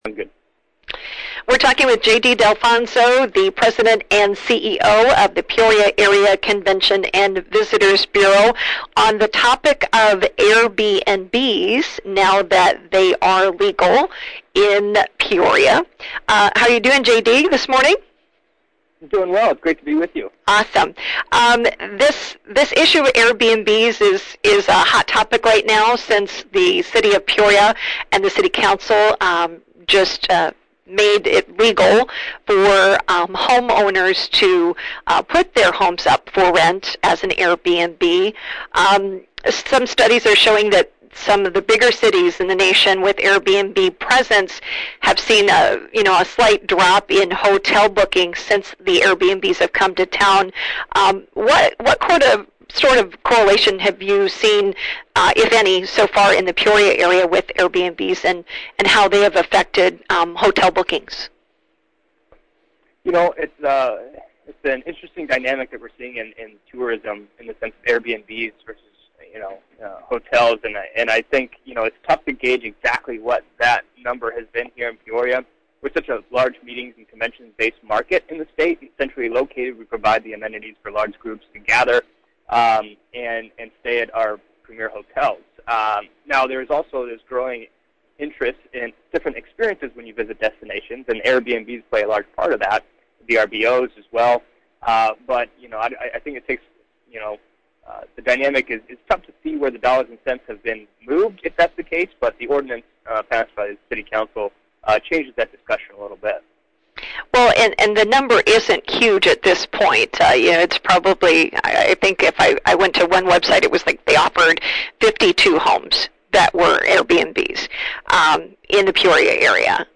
Here’s the full interview